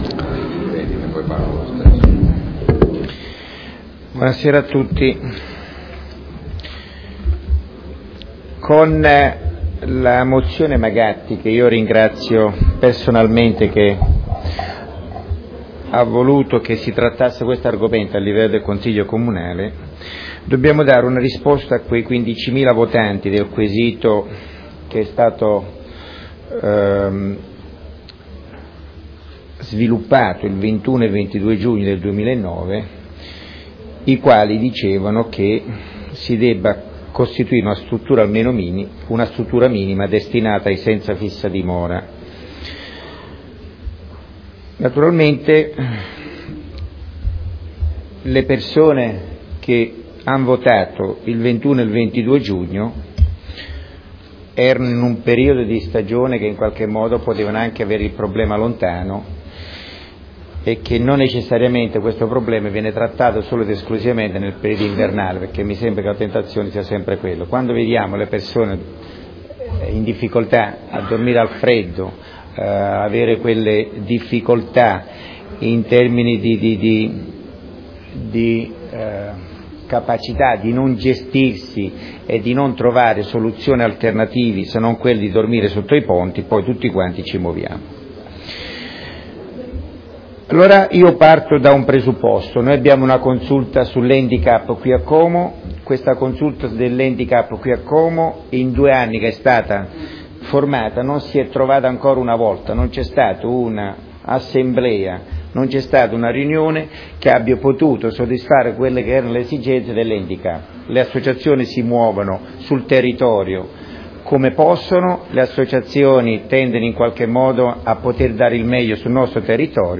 Riportiamo di seguito gli interventi audio dei consiglieri e dell'Assessore in occasione della bocciatura.
Podcast audio del Consiglio Comunale (mp3):